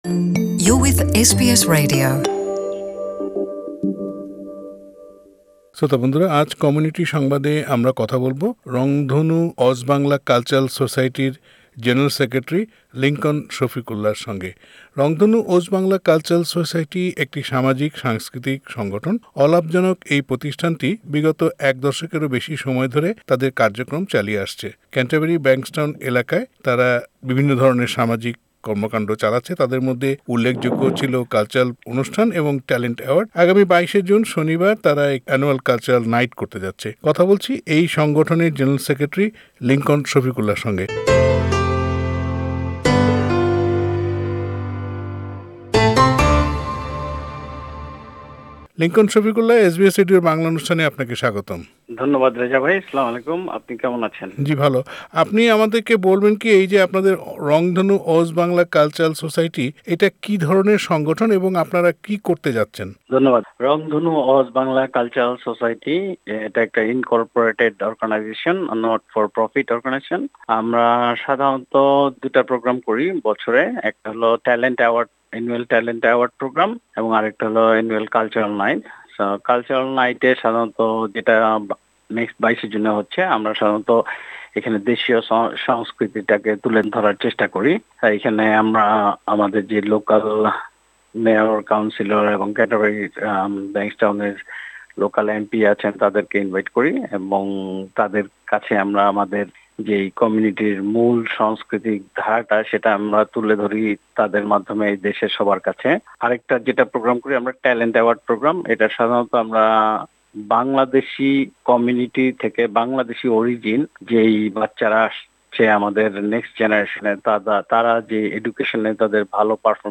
সাক্ষাৎকারটি